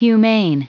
Prononciation du mot humane en anglais (fichier audio)
Prononciation du mot : humane